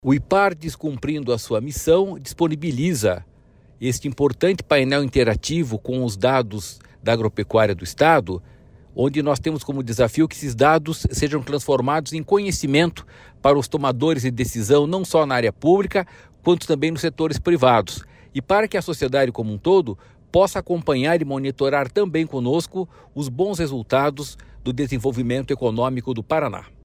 Sonora do presidente do Ipardes, Jorge Callado, sobre o Painel Agropecuária Paranaense